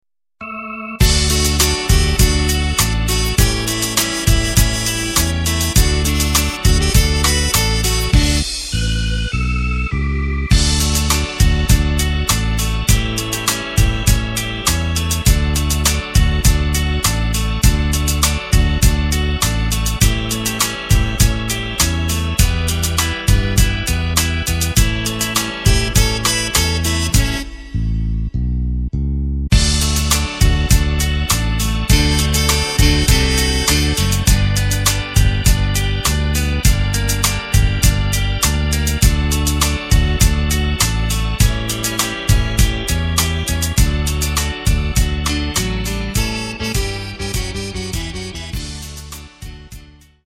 Takt:          4/4
Tempo:         101.00
Tonart:            D
Schlager aus dem Jahr 1967!
Playback mp3 Demo